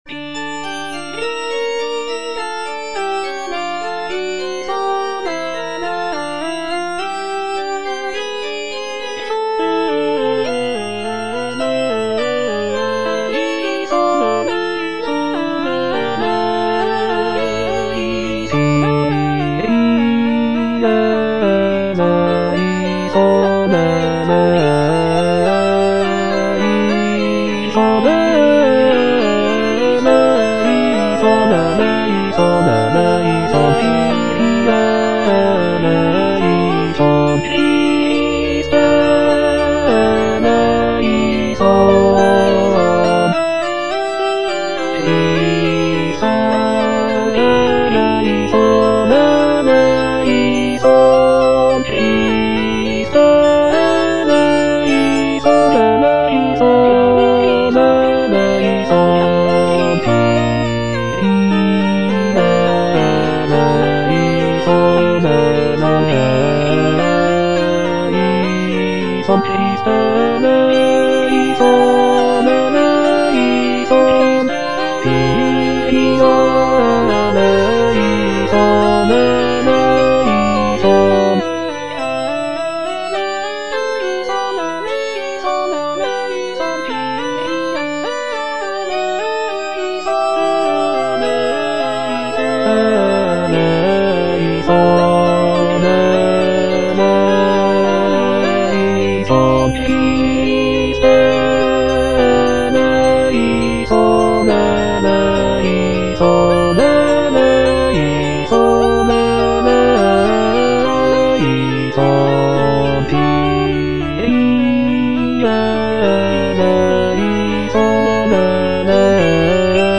F. VON SUPPÈ - MISSA PRO DEFUNCTIS/REQUIEM Kyrie - Bass (Emphasised voice and other voices) Ads stop: auto-stop